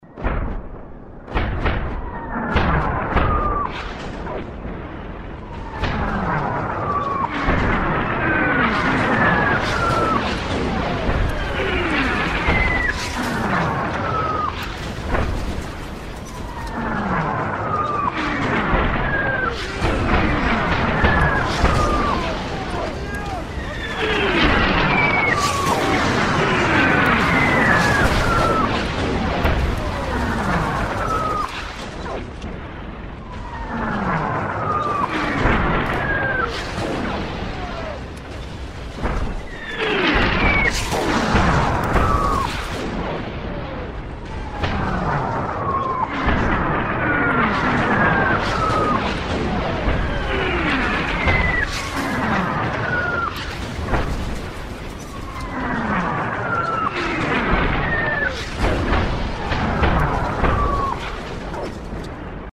Бомбёжка из старой военной киноленты